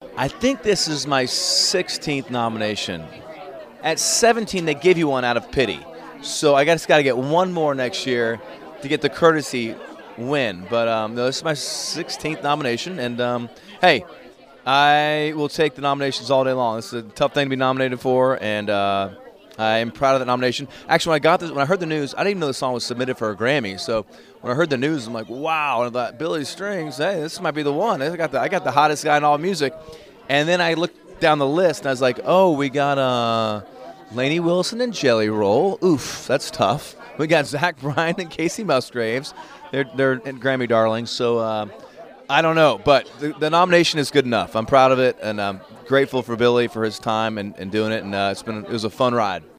Audio / Dierks Bentley jokes around about his GRAMMY nomination for Best Country Duo/Group Performance with Billy Strings.